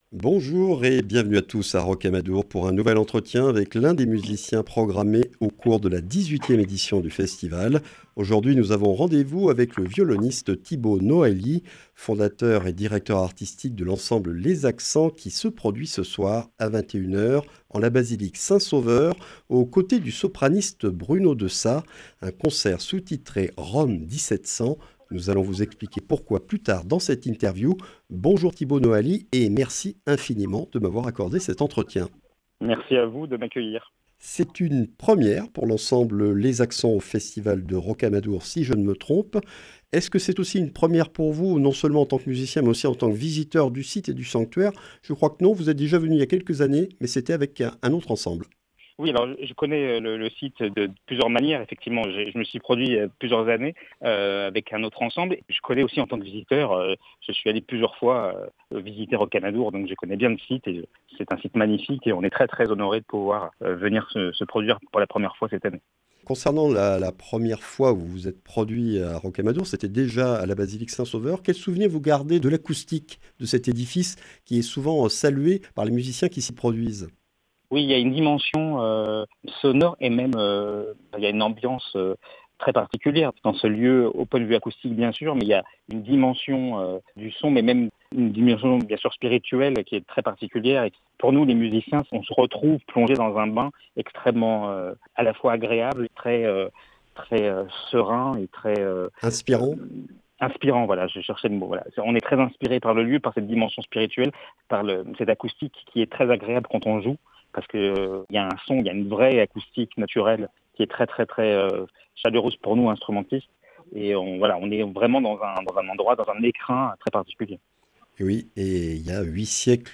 Festival de Rocamadour 2023, ITW du 19 août